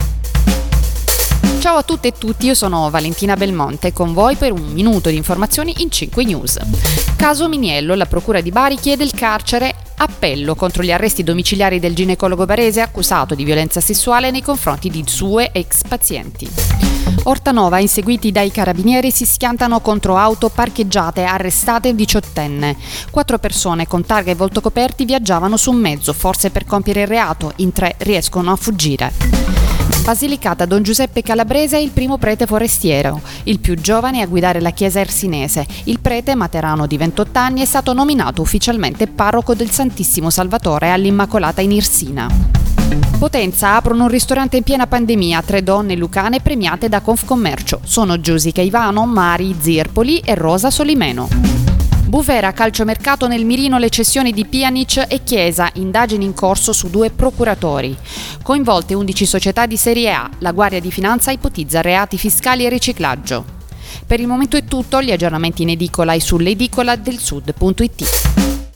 Giornale radio